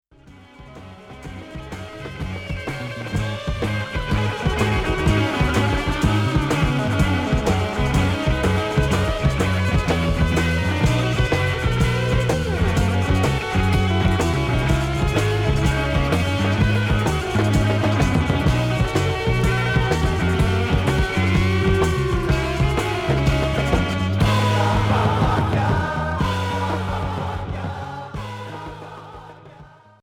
Heavy pop